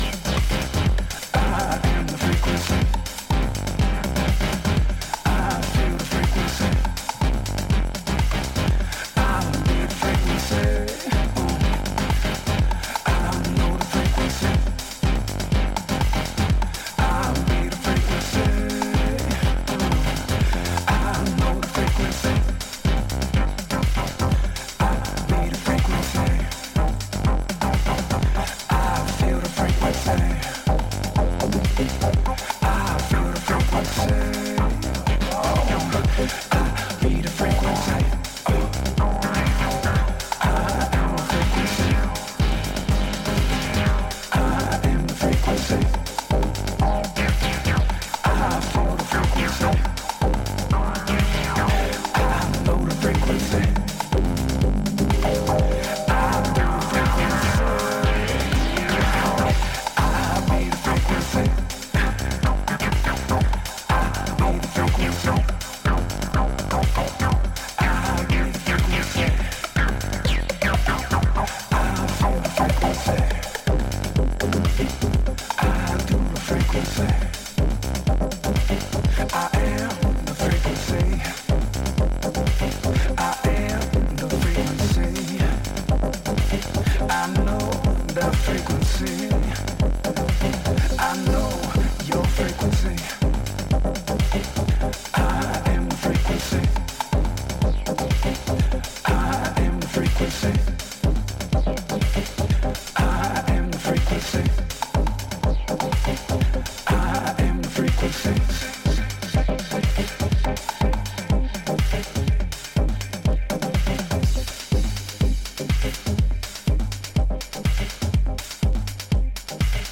vocalist
hypnotic, late-night deep house swell
where spacey synths and heady melodic loops catch the ear